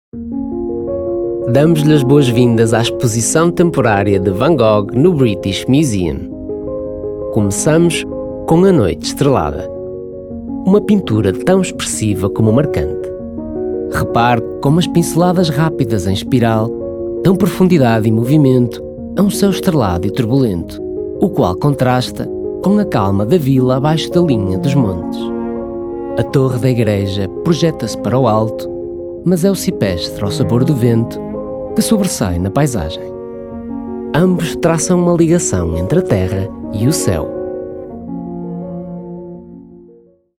Audioguides